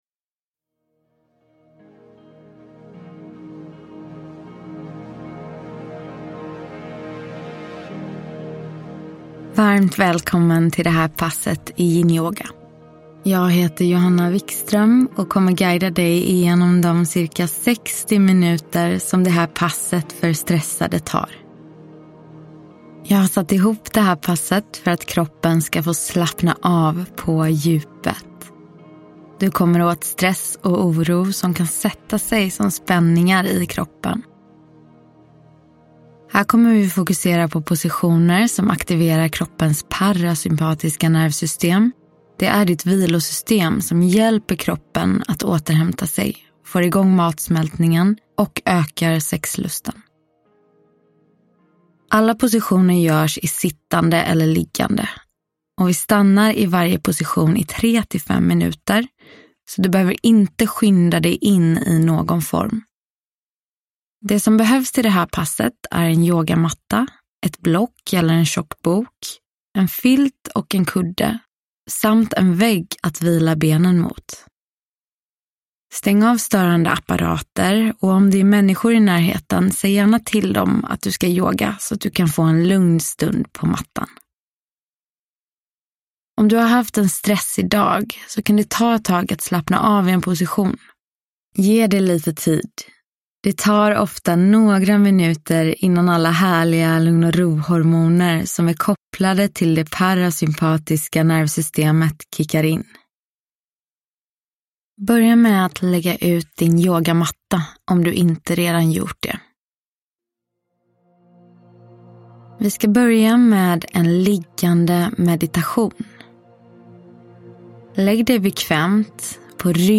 Yinyoga - Pass för stressade – Ljudbok